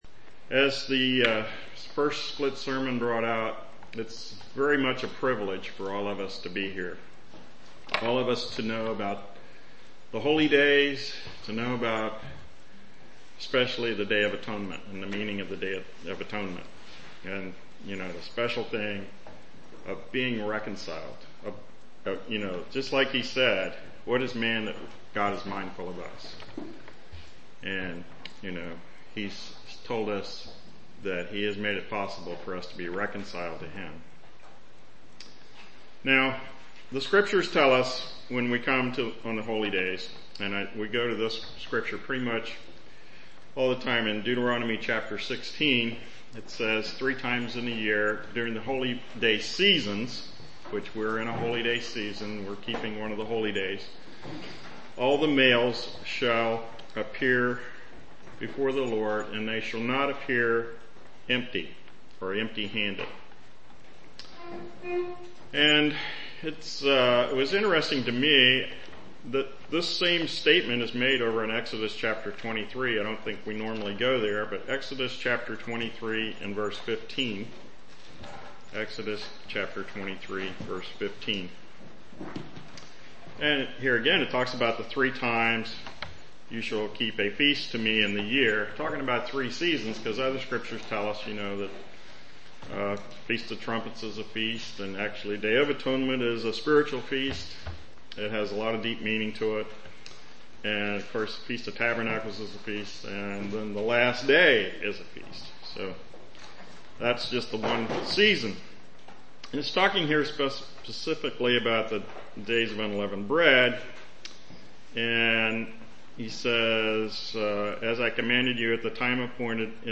Given in Lewistown, PA
UCG Sermon Studying the bible?